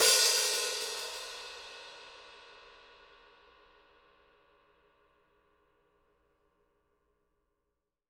R_B Hi-Hat 10 - Close.wav